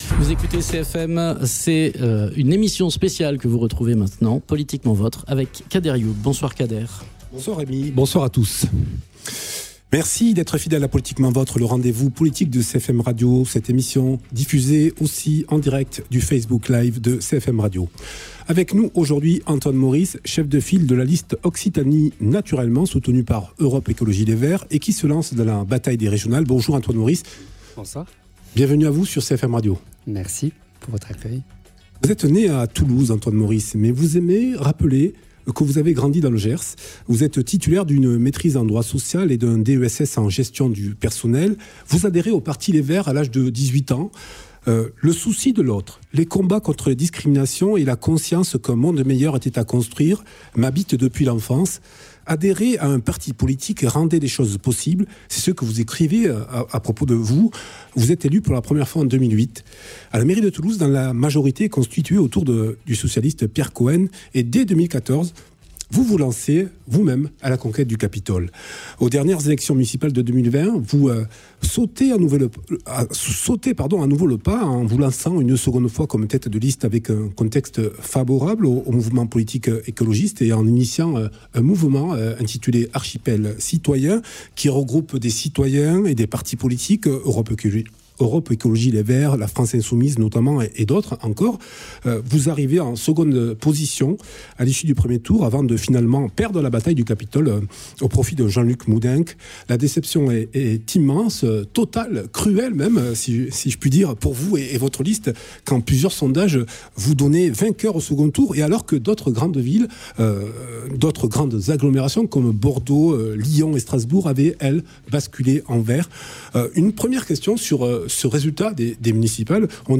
Grand plan pour la qualité de l’air, préservation de la biodiversité, démocratie sanitaire, agroécologie…. Antoine Maurice, chef de file du rassemblement des Écologistes et des Régionalistes pour les élections régionales du 20 et 27 juin 2021 en Occitanie et Pays catalan était en direct sur CFM Radio, jeudi 27 mai 2021 pour présenter son projet.
Invité(s) : Antoine Maurice, Tête de liste Occitanie Naturellement, Europe Écologie Les Verts (EELV)